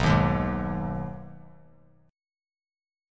Listen to B6 strummed